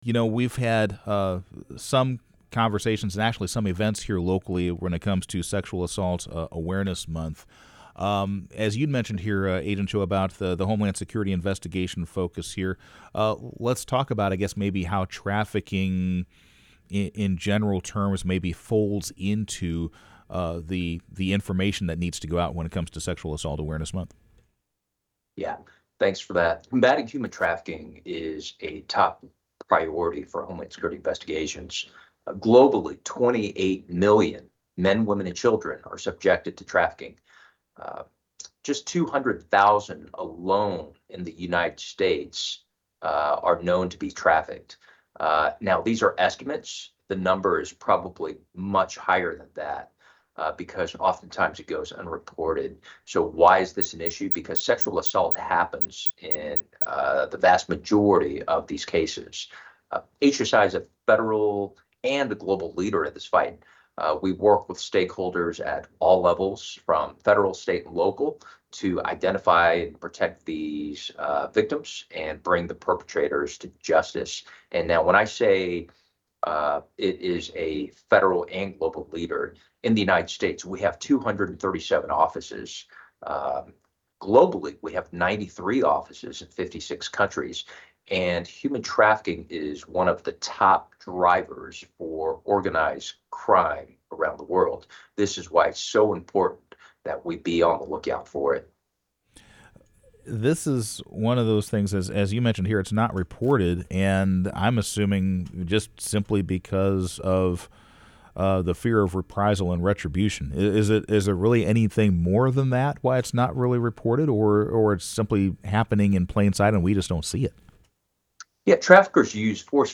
KVOE News interview